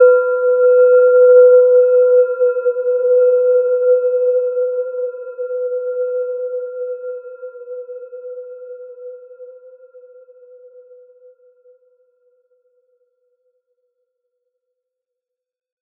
Gentle-Metallic-4-B4-mf.wav